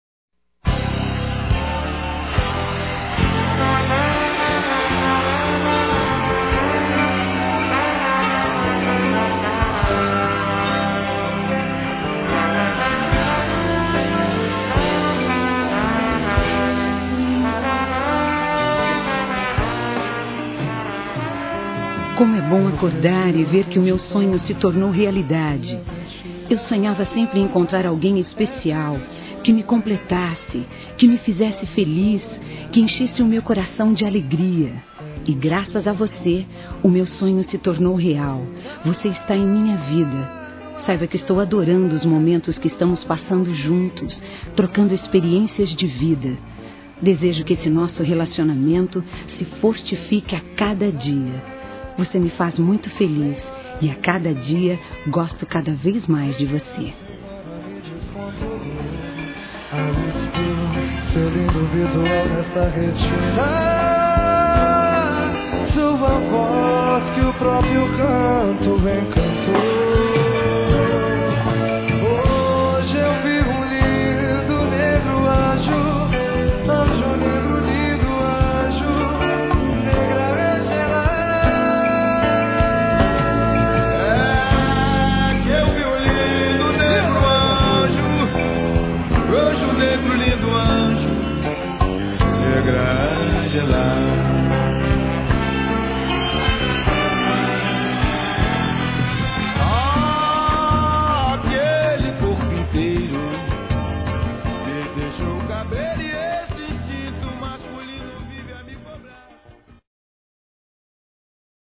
Meu Sonho | Voz Feminina
Mensagens Fonadas